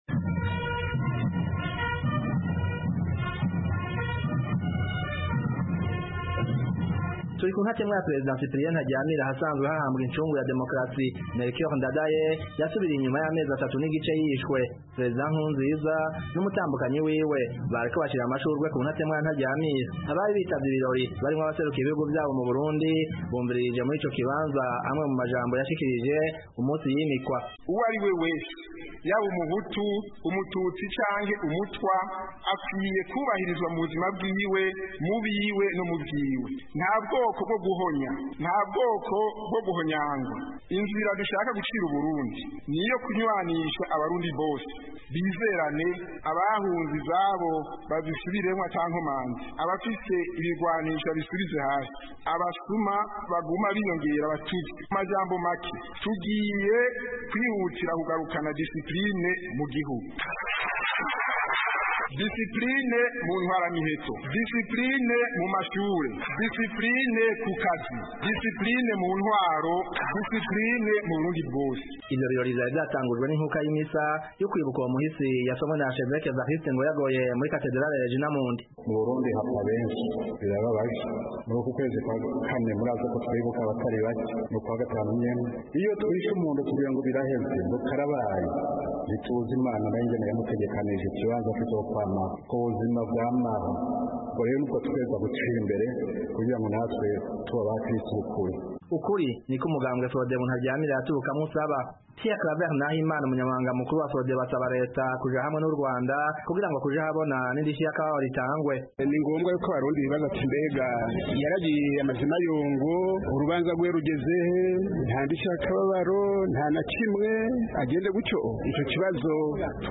Ibindi twumvirize Umumenyeshamakuru w'Ijwi ry'Amerika m'Uburundi